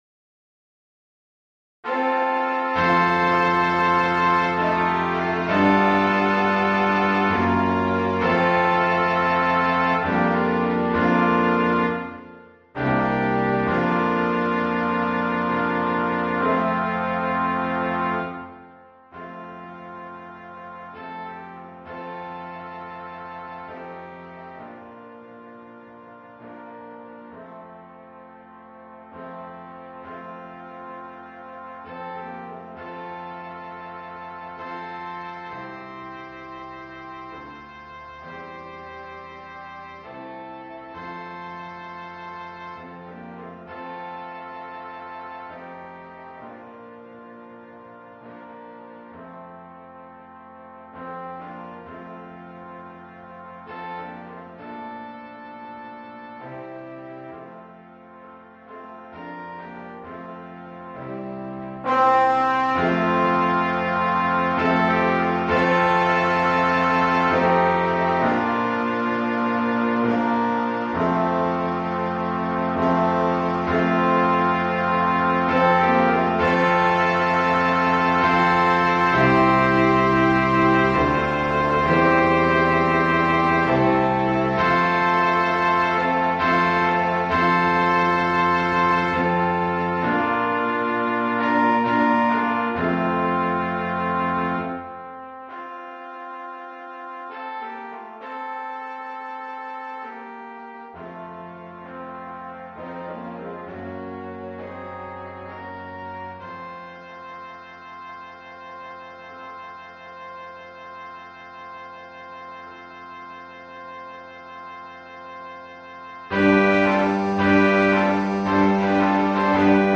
Gattung: für variables Quintett
Besetzung: Ensemble gemischt
Piano, Organ & Percussions optional.